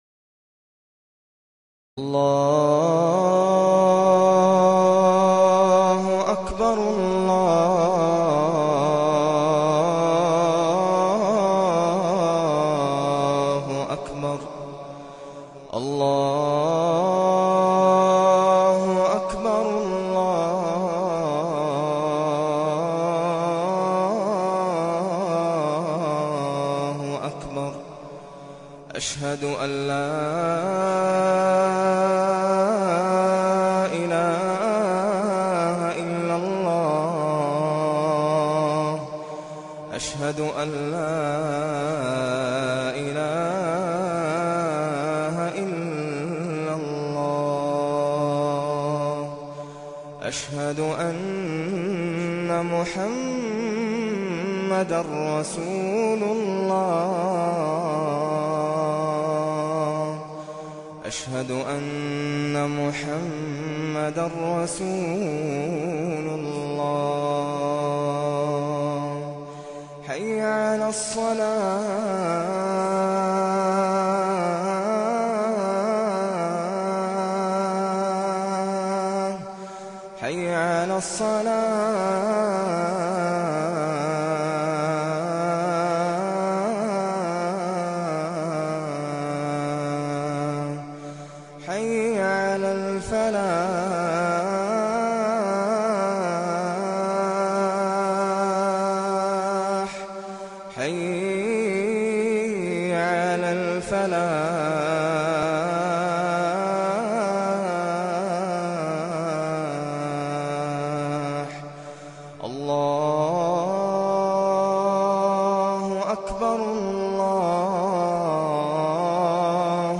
This ritual is called out by the muezzin from the mosque, and it is mandatory amongst the Muslims.
Call to Prayer (Adhan or Azan)
Amazing-Adhan-must-listen.mp3